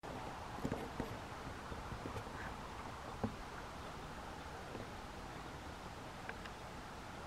Krustknābis Loxia sp., Loxia sp.
Administratīvā teritorijaEngures novads
Skaits5